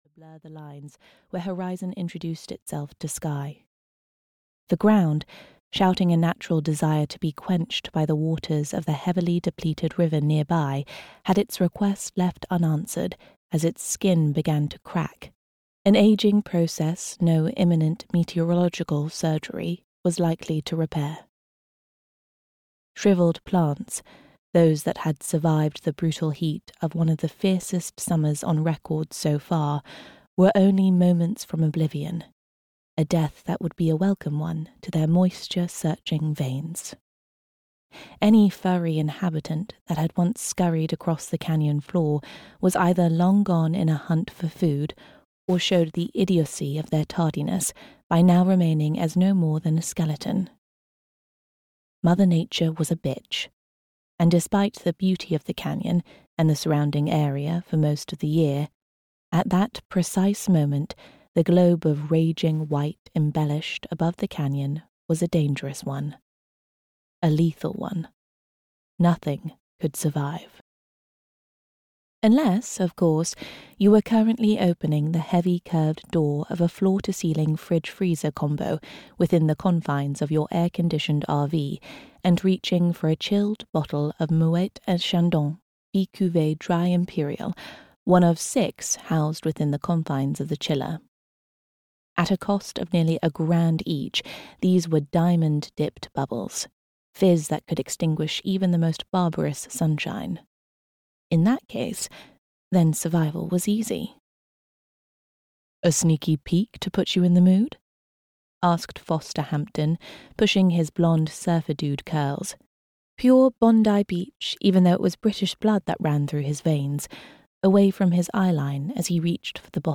Scandalous Lies (EN) audiokniha
Audiobook Scandalous Lies written by Nigel May. Lovers and liars, divas and sinners, welcome to a world of scandal, where one woman will pay the ultimate price.
Ukázka z knihy